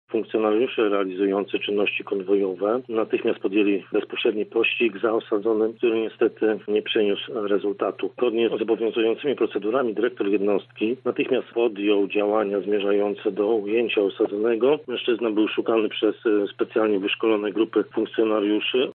– mówi major